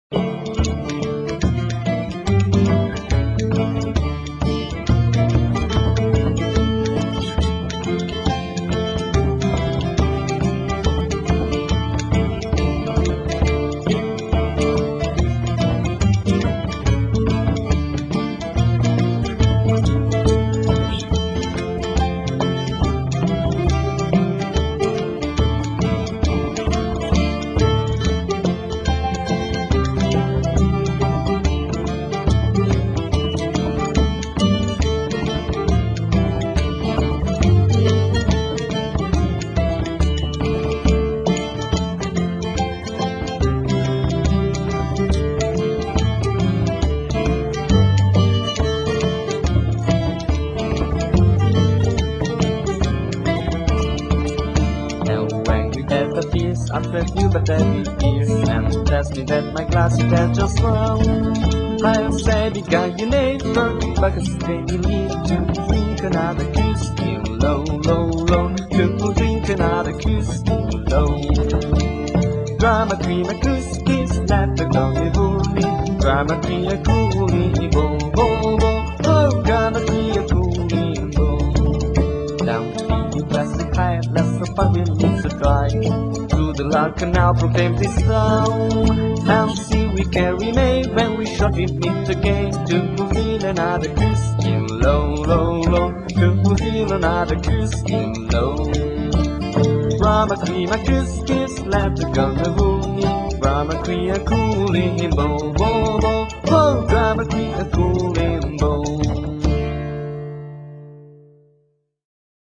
(song)
vocal, guitar, mandolin
mandola, bouzouki
bodhrán, spoons.